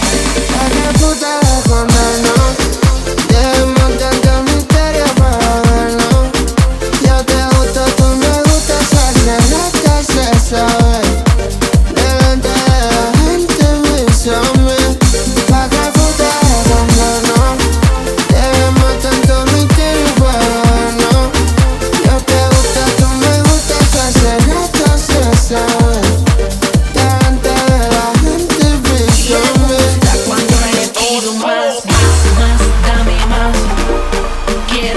Latin Urbano latino
Жанр: Латино